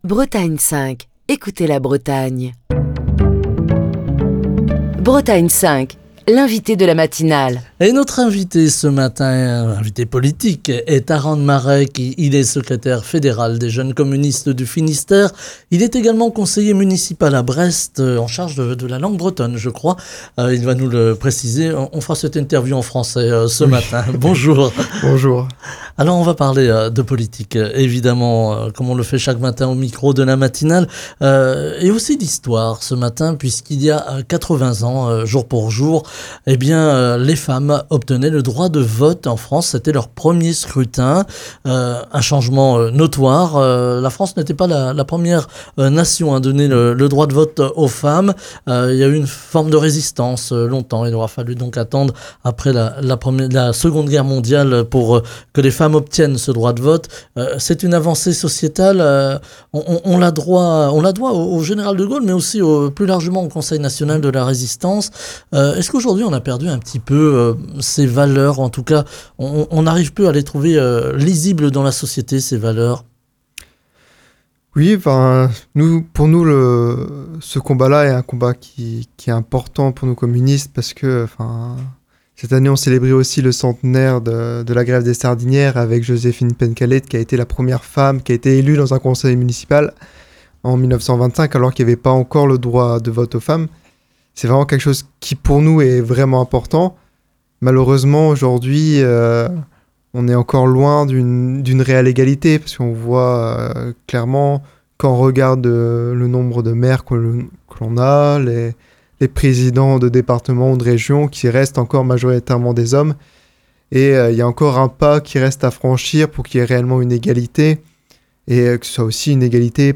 Taran Marec, secrétaire fédéral des Jeunes communistes du Finistère et conseiller municipal à Brest, était l'invité de la matinale de Bretagne 5 ce mardi.